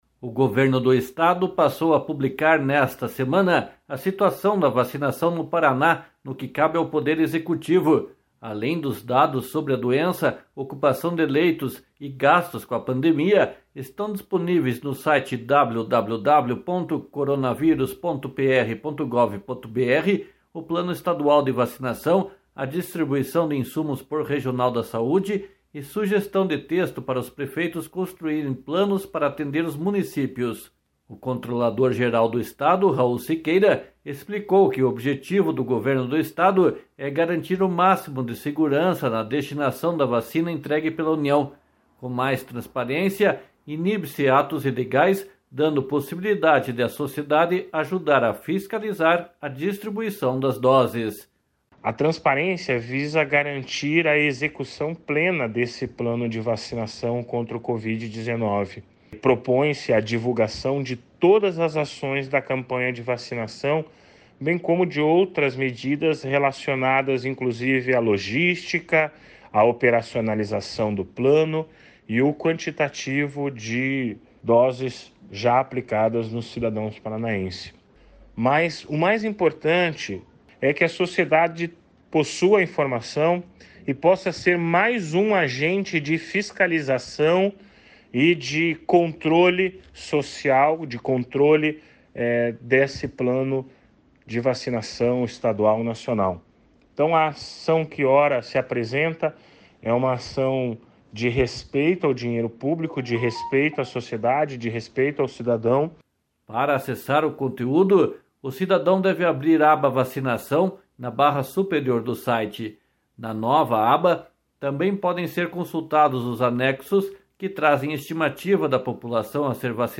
//SONORA RAUL SIQUEIRA//